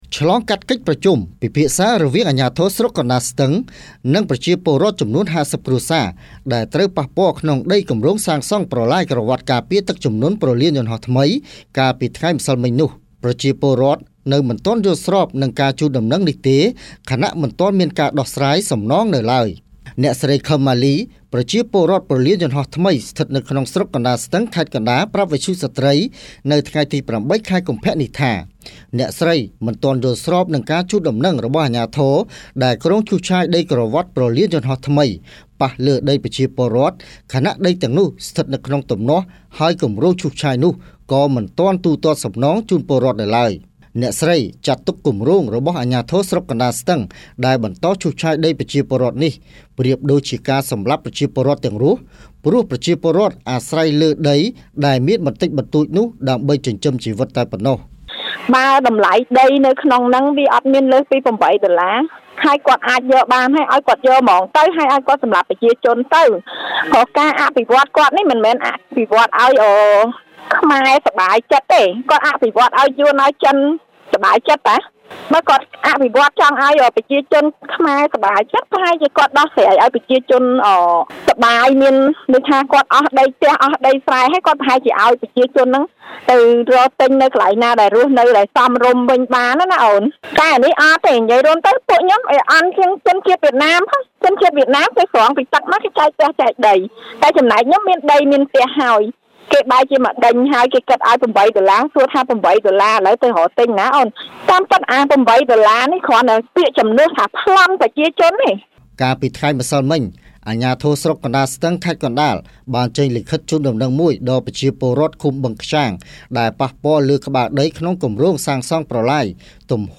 រាយការណ៍ពីព័ត៌មាននេះ